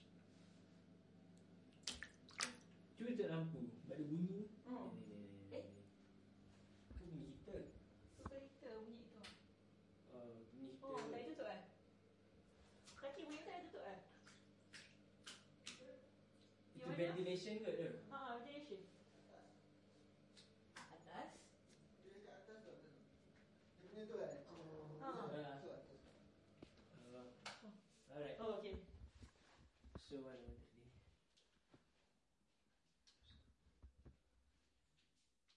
脚步声 浴缸（水） 2
描述：脚步声水浴缸声音环境自然周围的田野录音 环境foley录音和实验声音设计。
Tag: 脚步 浴缸 声音 周围 现场记录 自然 环境